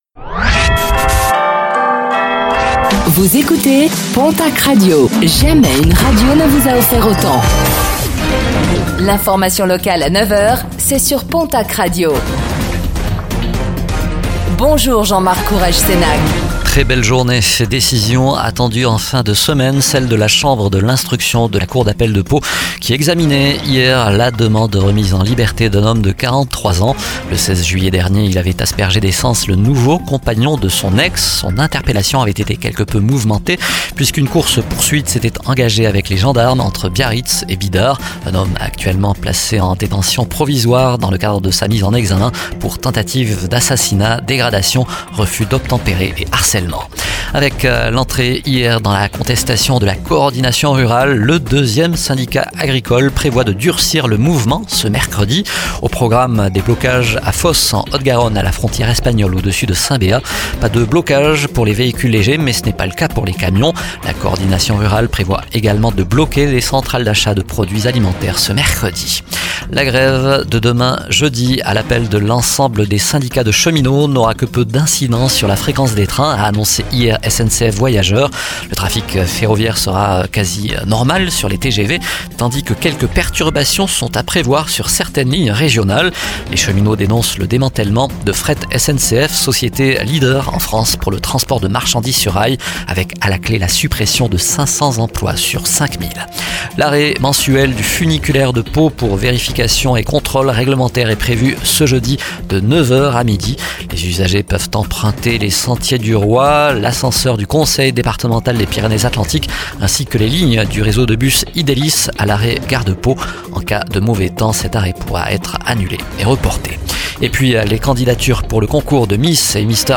Infos | Mercredi 20 novembre 2024